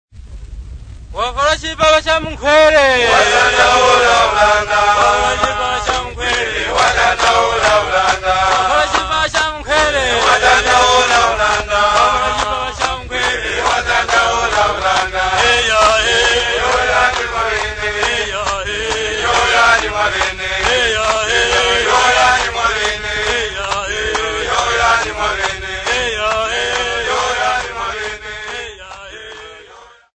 Henga / Tumbuka men
Popular music--Africa
Field recordings
sound recording-musical
Indigenous music